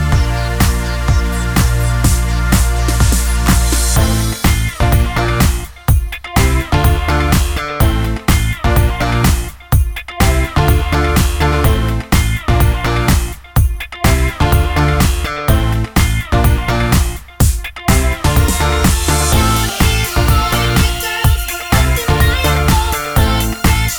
rap section cut Pop (2000s) 3:16 Buy £1.50